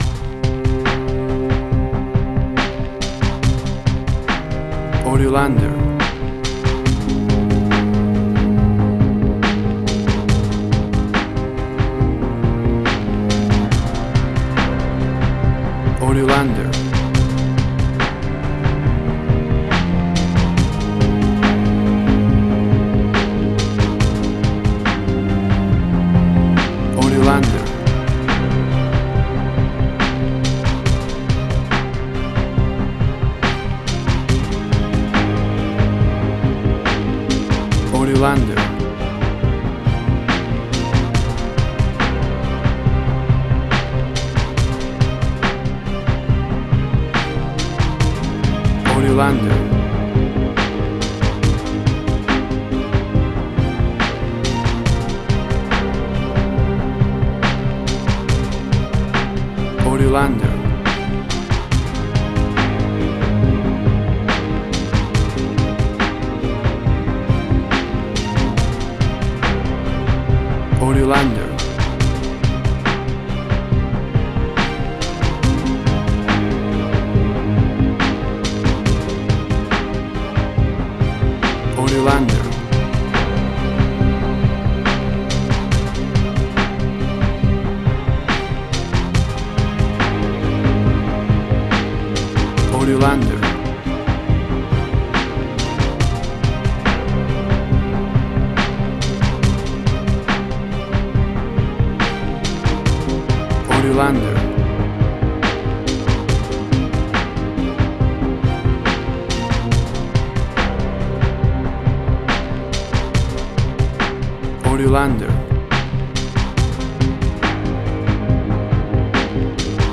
Trip hop style, smooth, with strings ambient
WAV Sample Rate: 24-Bit stereo, 48.0 kHz
Tempo (BPM): 69